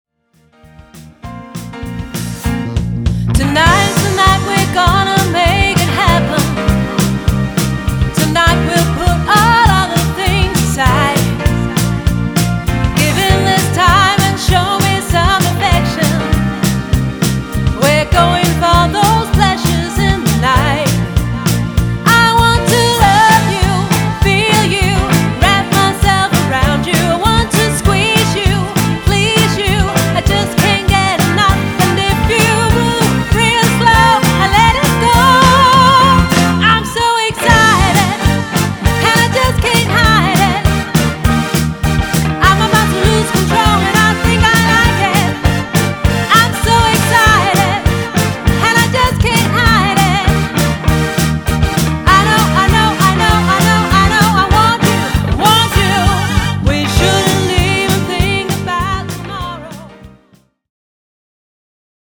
• Coverband